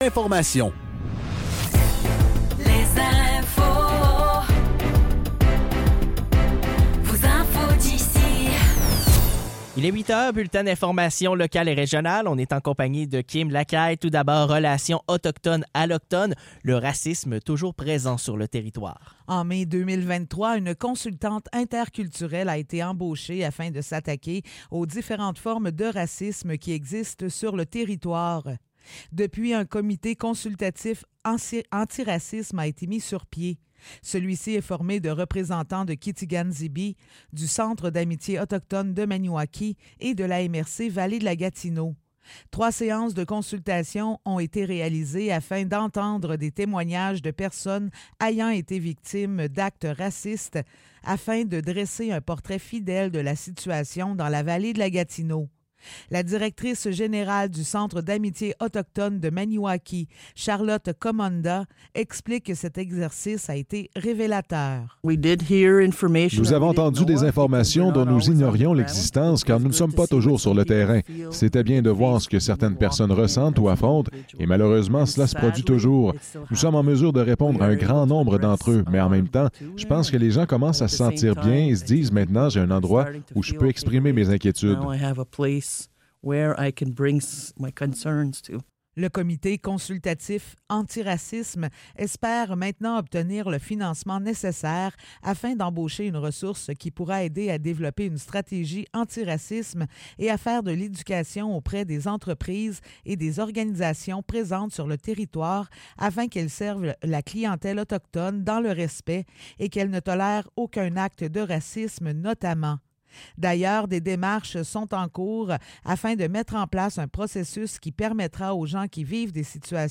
Nouvelles locales - 5 août 2023 - 8 h | CHGA